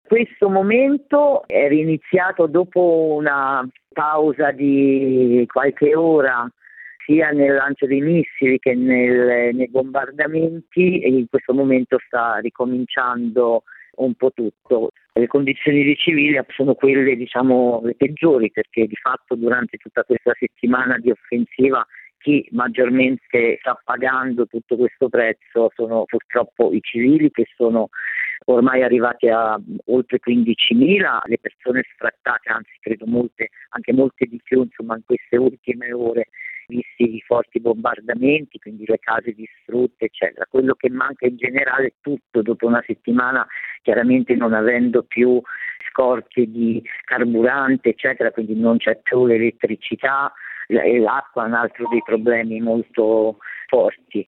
Il racconto della giornata di martedì 18 maggio 2021 con le notizie principali del giornale radio delle 19.30. Dopo l’invito ad aprire ai 40enni per alzare il numero dei vaccinati, Figliuolo ci ripensa e dice alle regioni di concentrarsi su 60enni e 70enni e non fare propaganda, mentre il progetto del vaccino italiano Reithera può considerarsi fallito.